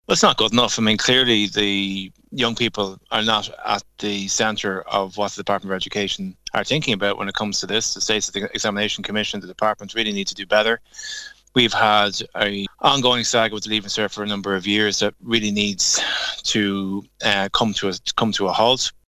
Labour’s spokesperson on Education Aodhán Ó Ríordáin says a radical overall of the Leaving Cert is needed: